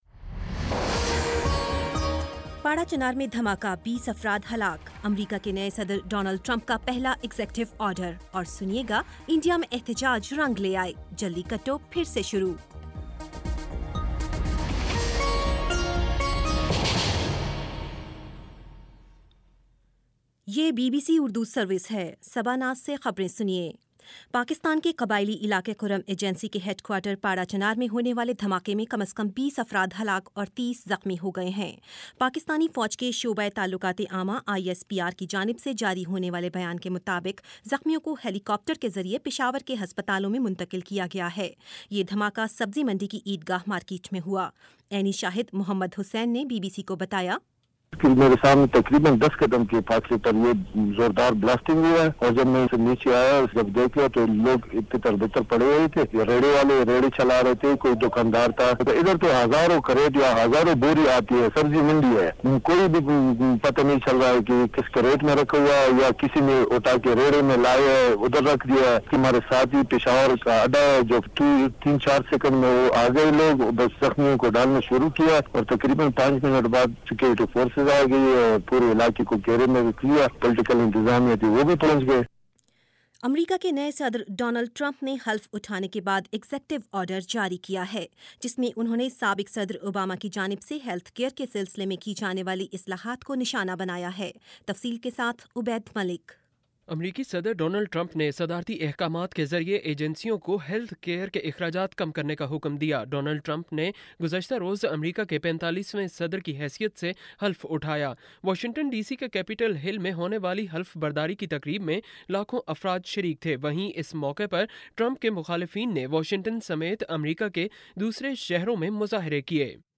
جنوری 21 : شام چھ بجے کا نیوز بُلیٹن